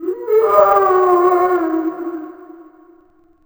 c_zombim1_dead.wav